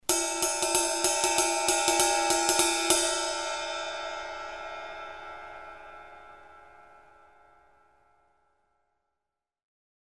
Zildjian 20" K Custom Hybrid Ride Cymbal
Unlathed, buffed center provides defined stick attack and strong bell.
The unlathed, buffed center provides defined stick attack and a strong bell, while the outside lathed edge increases spread and crashability.... Crash Ride Ride Bell